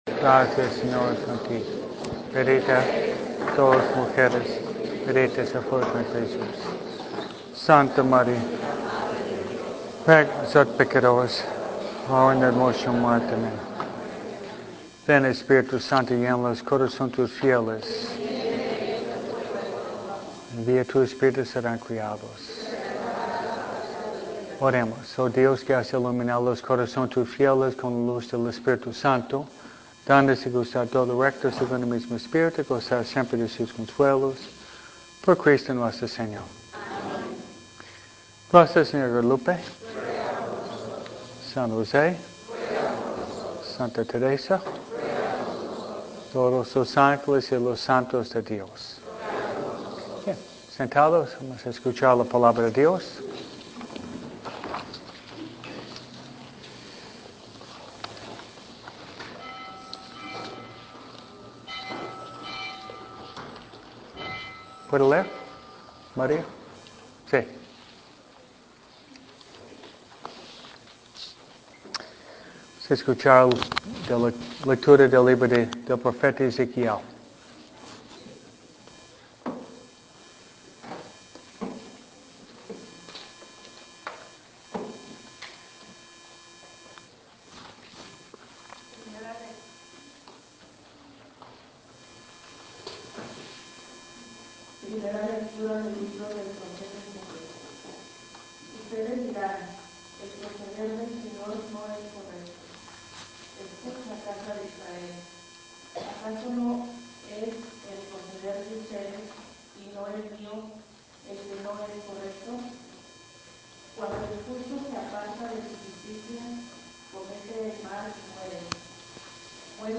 MISA – HOMILIA
MISA-HOMILIA-5.mp3